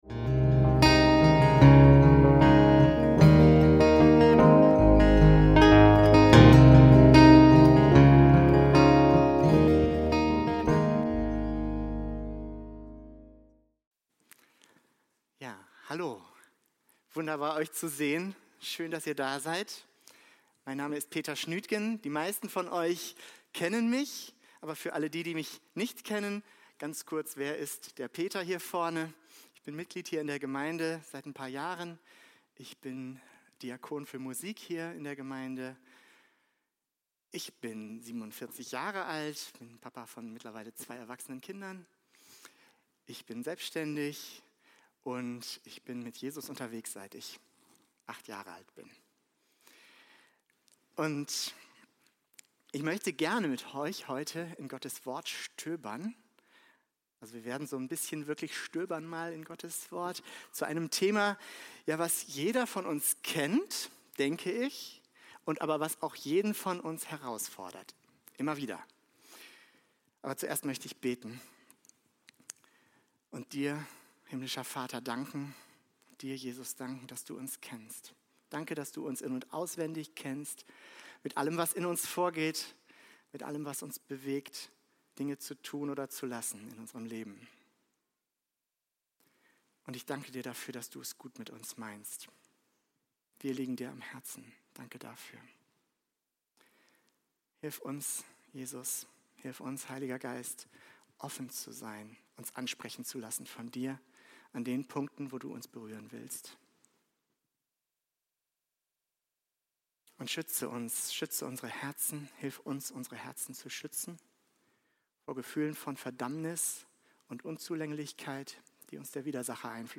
Sammelt euch Schätze - Predigt vom 17.08.2025